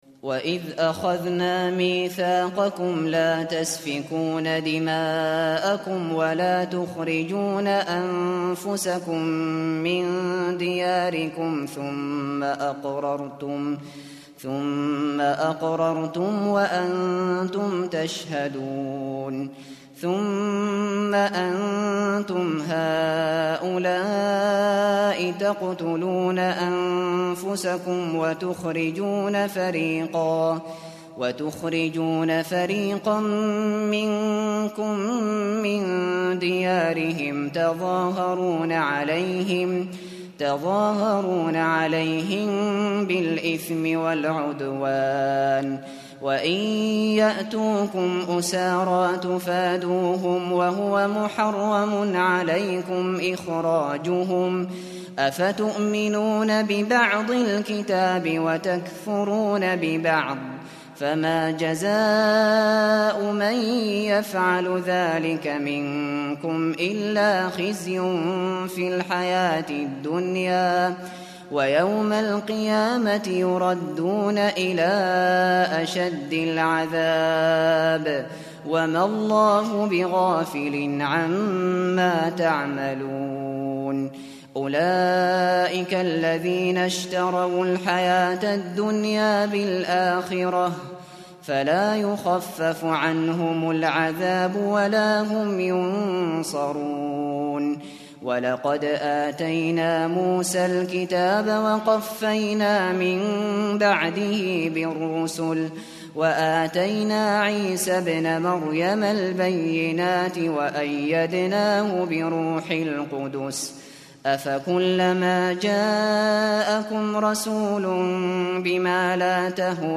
Kur'ân dinlemeye başlamak için bir Hafız seçiniz.
Hafız Abu Bakr al Shatri sesinden Cüz-1, Sayfa-13 dinle!
Hafız Maher Al Mueaqly sesinden Cüz-1, Sayfa-13 dinle!
Hafız Mishary AlAfasy sesinden Cüz-1, Sayfa-13 dinle!